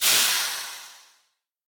train-breaks-4.ogg